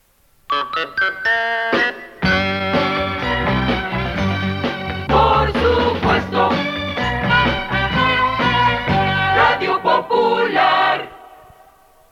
Indicatiu cantat